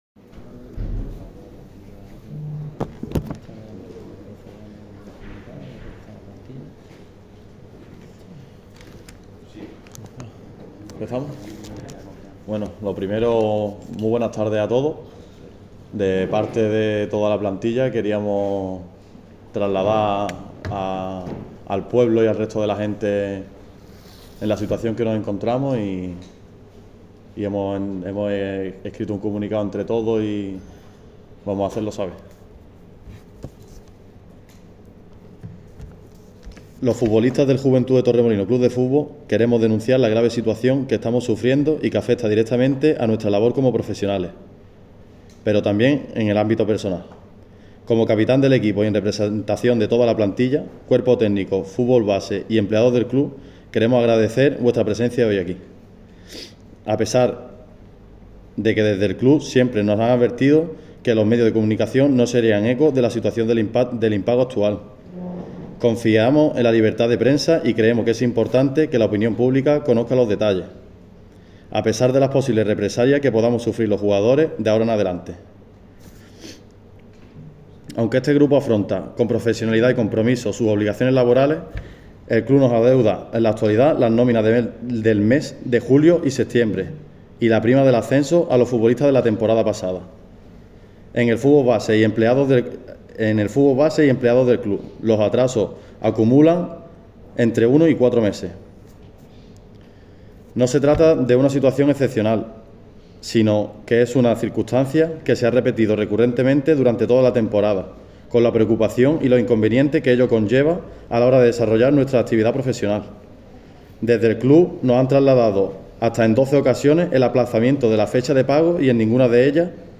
Hoy, a partir de las 13:00h, los jugadores del Juventud de Torremolinos han hablado en La Caseta La Biznaga, situada en las cercanías de El Pozuelo, para dar explicaciones sobre su actual situación económica.
Aquí sus palabras, primero mediante el comunicado y a contonuación atendiendo a las preguntas de los periodistas.
Comunicado oficial de la plantilla